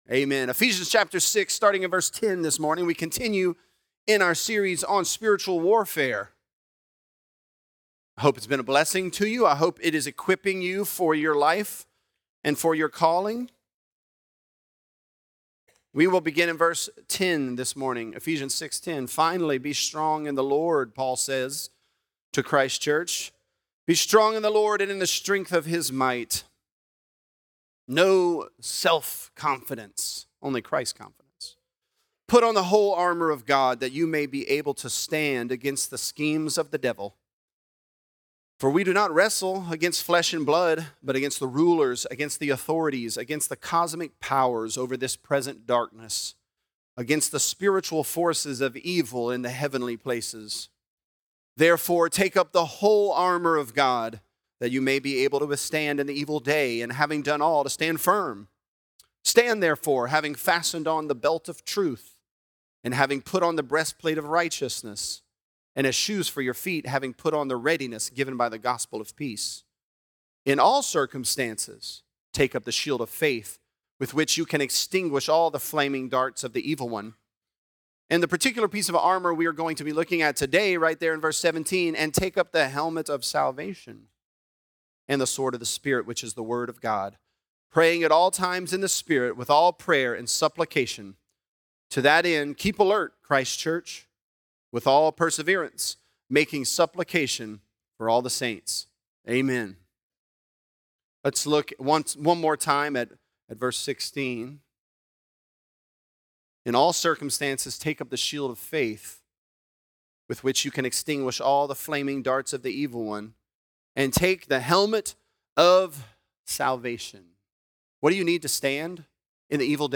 The Armor Of God: The Helmet Of Salvation | Lafayette - Sermon (Ephesians 6)